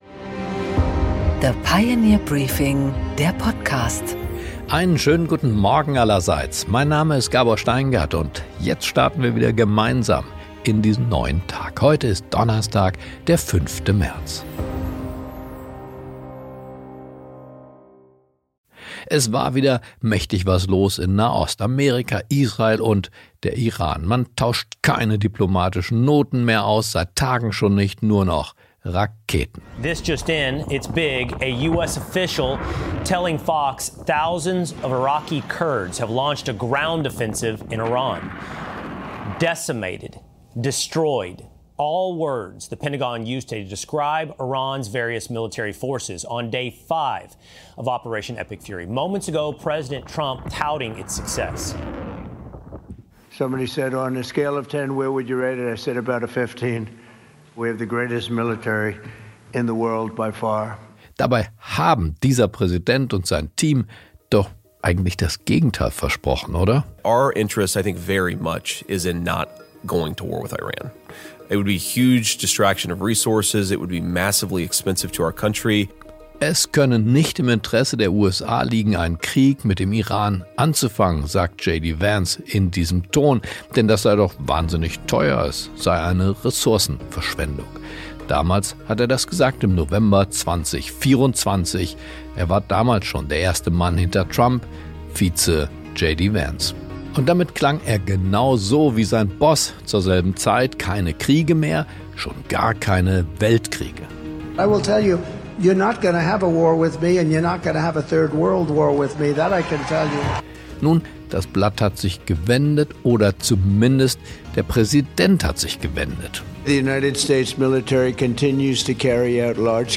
Gabor Steingart präsentiert das Pioneer Briefing.